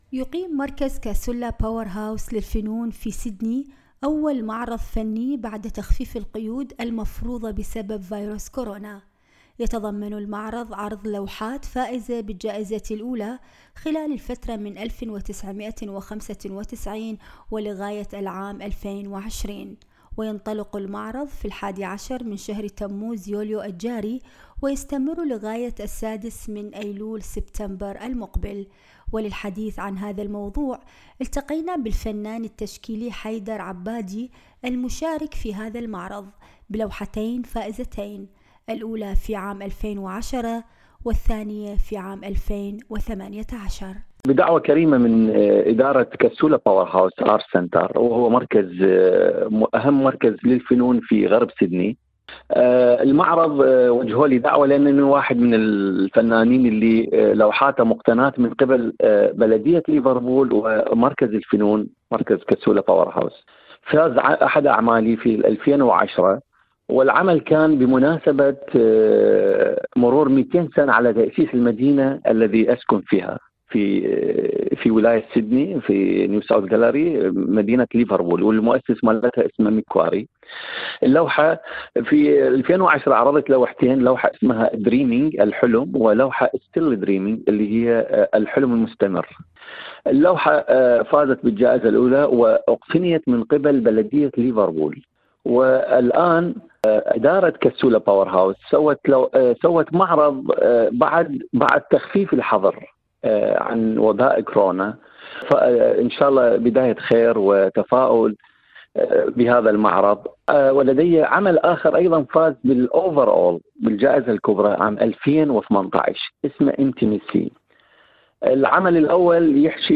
في حديثِ مع إذاعة أس بي أس عربي 24